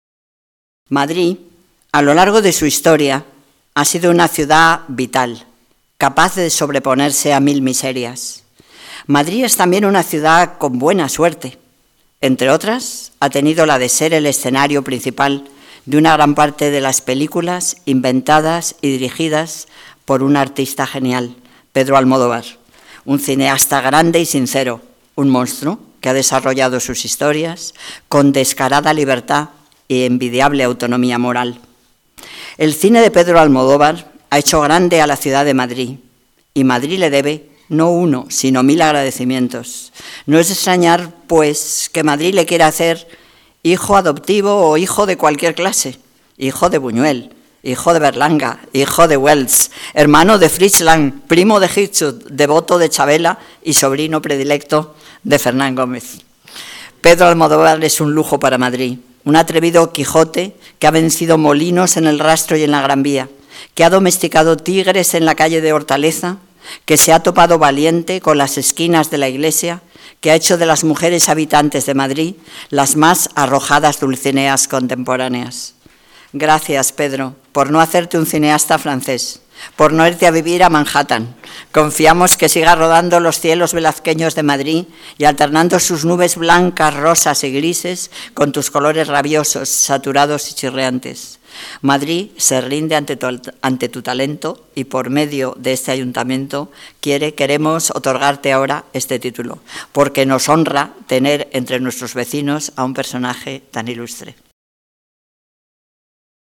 Esta mañana se ha celebrado la sesión plenaria en la que se han concedido las distinciones a los dos artistas